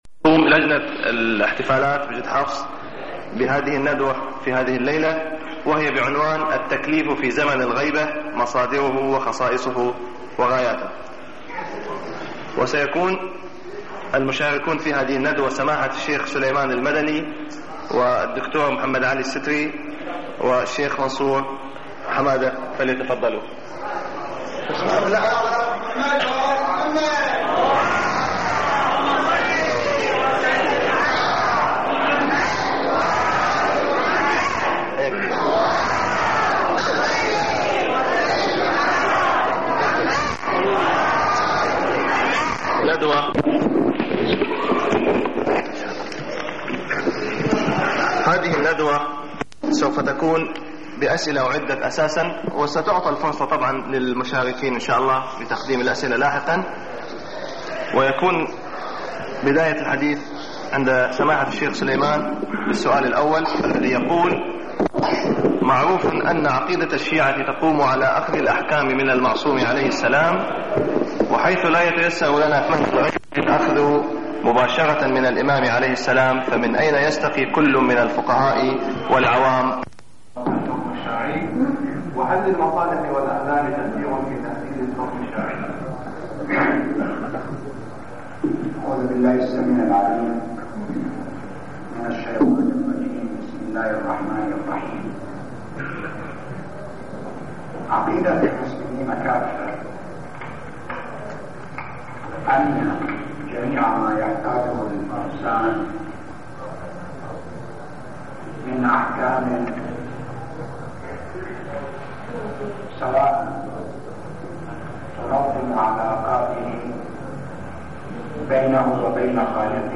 الندوات